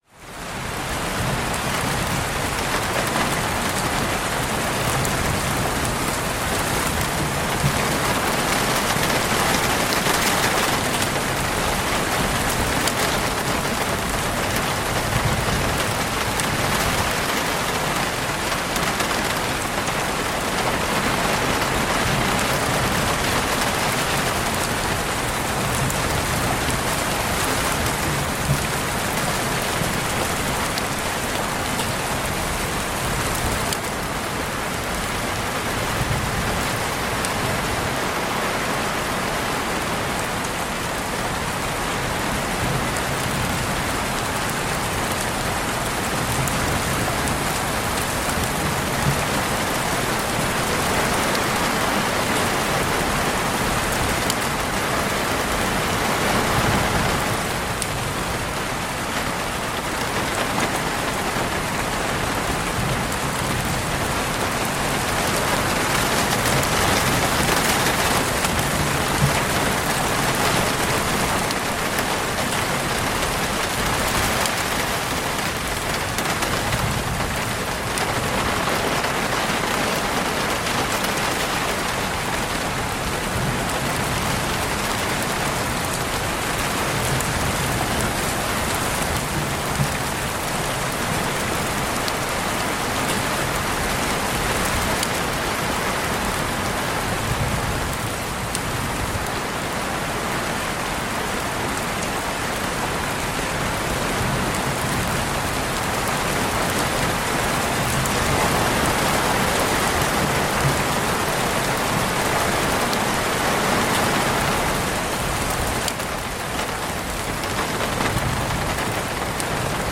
Sleep Through the Night with This Heavy Rain | Immediate Rest Effect
(Ads may play before the episode begins.)The sky opens with a low growl of thunder.